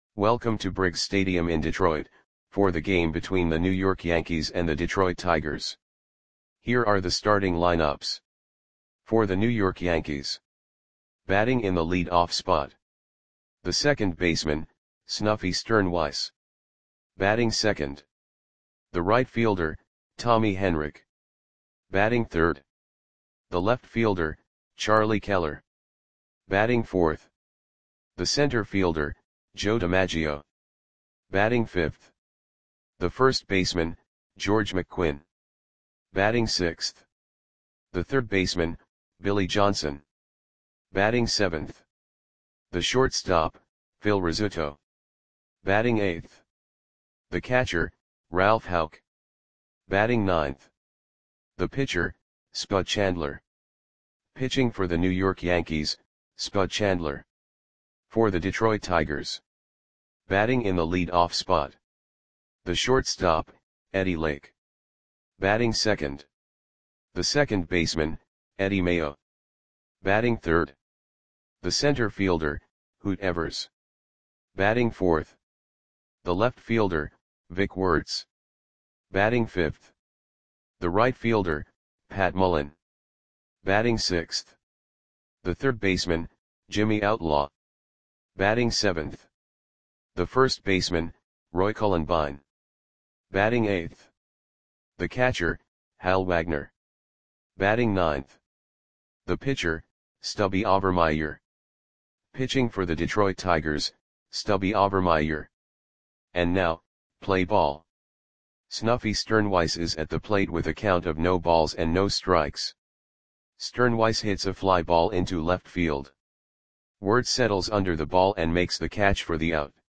Audio Play-by-Play for Detroit Tigers on June 5, 1947
Click the button below to listen to the audio play-by-play.